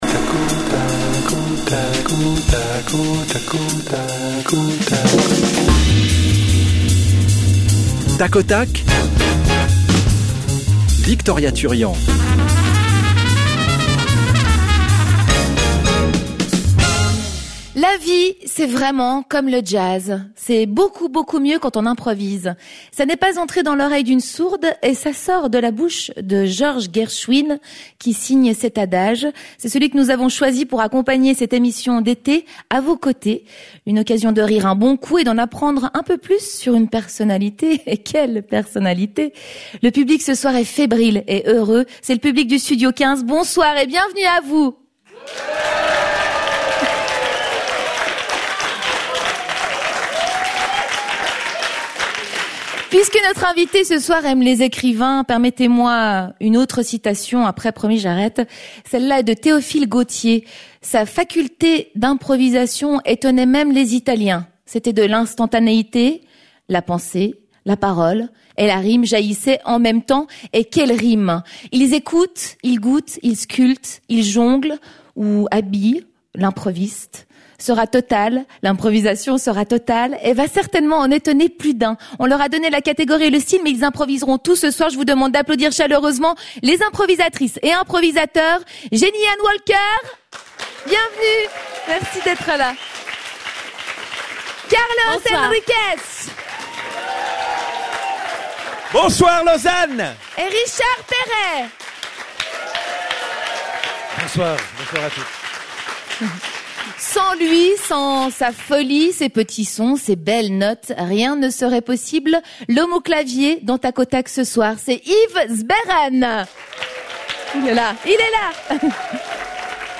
Nos improvisateurs reçoivent aujourd’hui Darius Rochebin